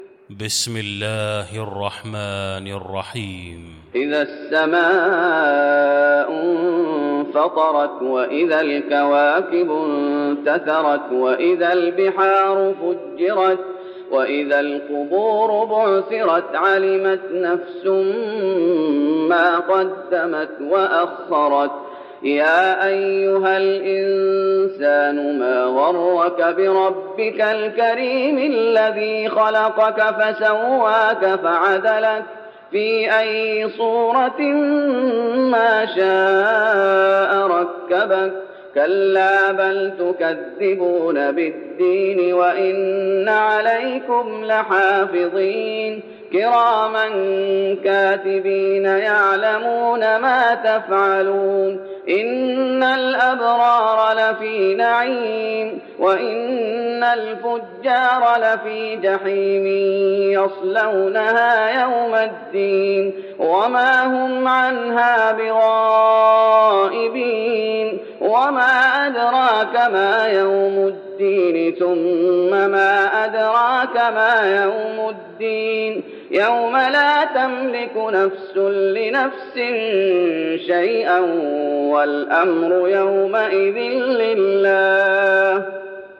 المكان: المسجد النبوي الانفطار